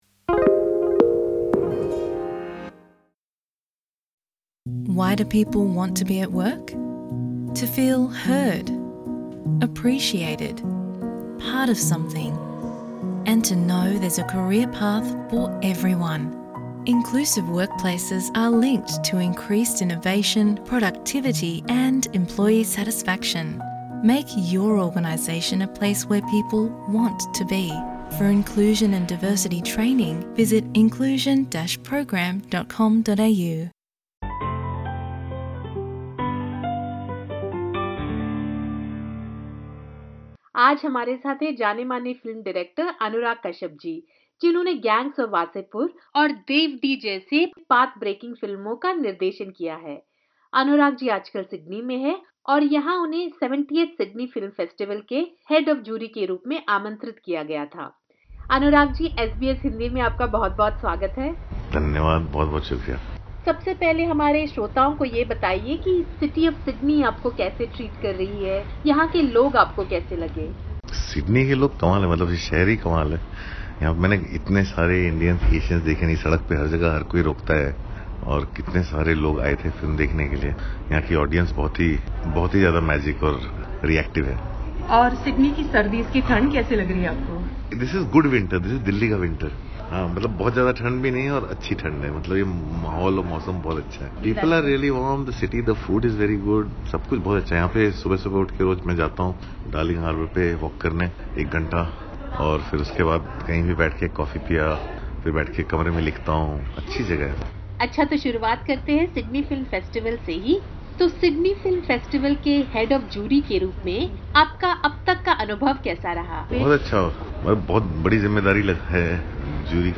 He spoke to SBS Hindi about the changing paradigms of Indian cinema, the role of women in the industry and the evolution of his own work.